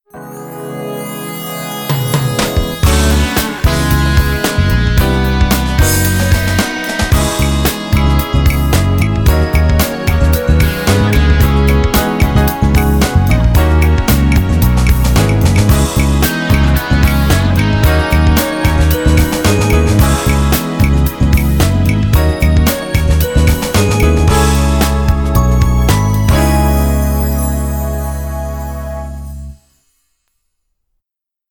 -Tempo Médium